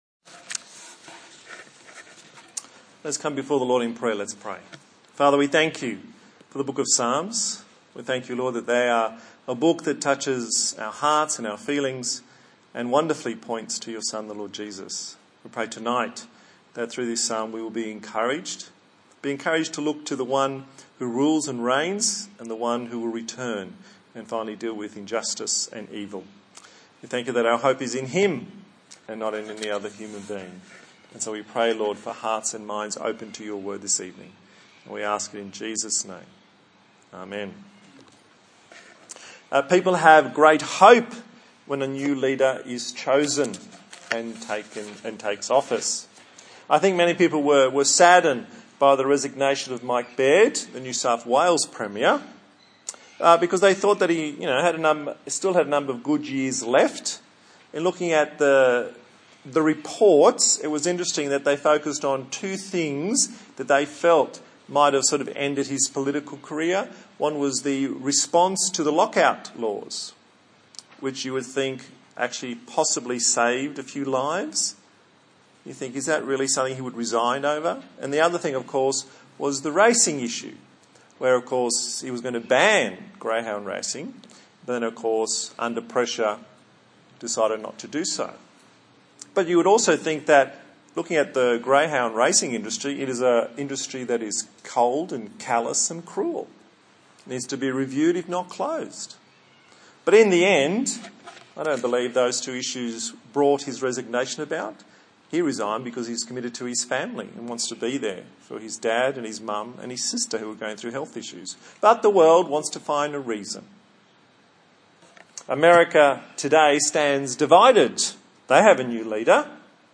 Psalms Passage: Psalm 21 Service Type: TPC@5